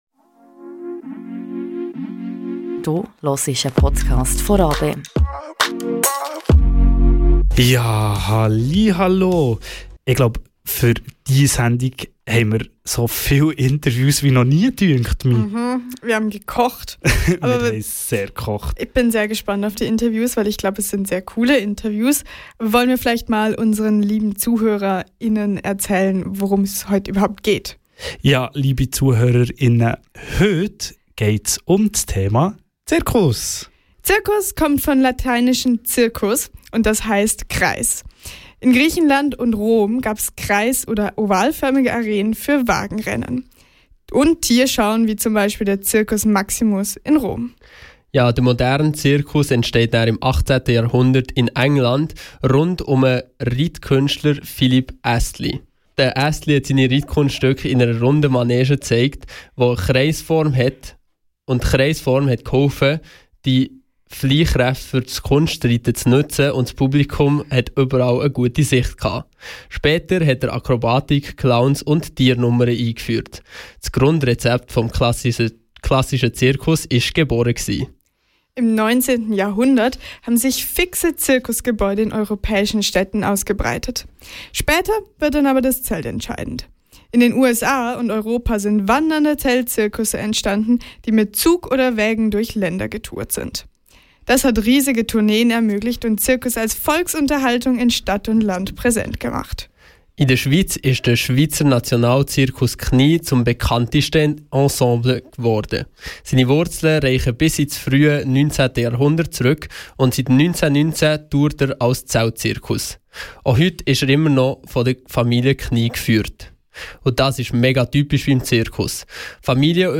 Mit dem immer wärmeren Temperaturen beginnt auch die neue Zirkussaison. In dieser Folge blicken wir hinter die Kulissen der wandernden Bühnenshow - zusammen mit einem Seiltänzer, einem Zauberer und einem Zirkus-Geschäftsleiter!